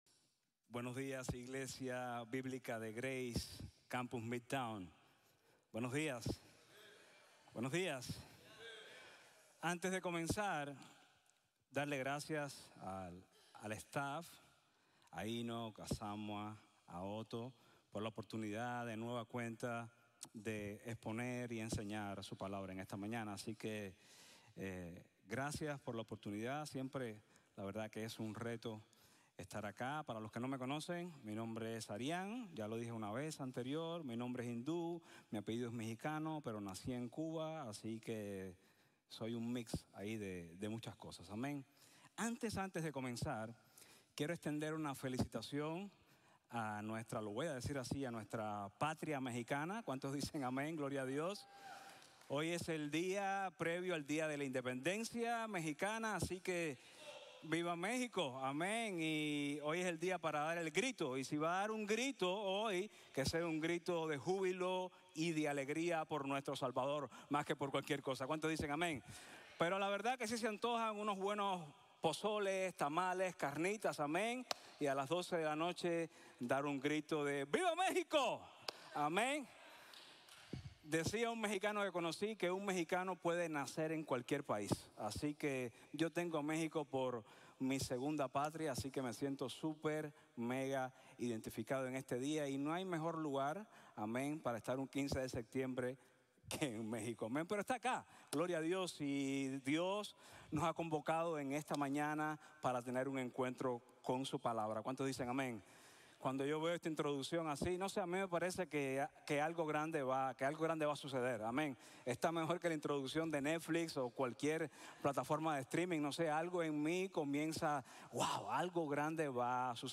Un Plan, mucha esperanza | Sermon | Grace Bible Church